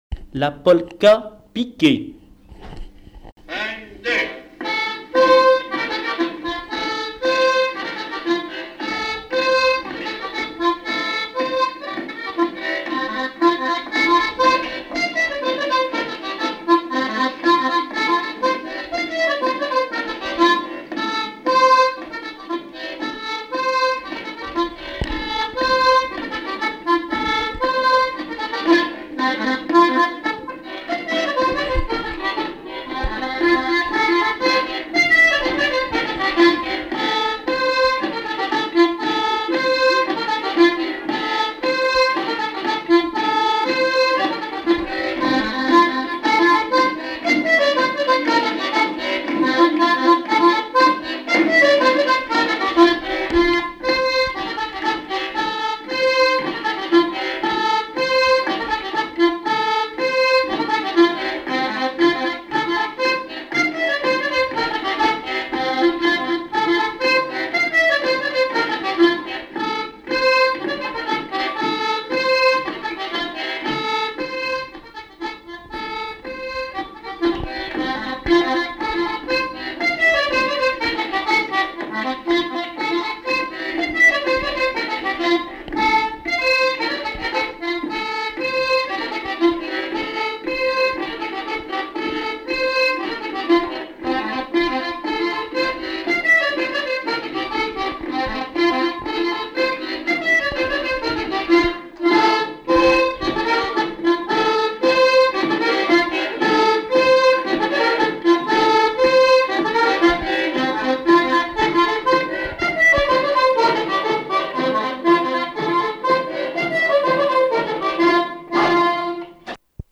danse : polka piquée
Répertoire du violoneux
Pièce musicale inédite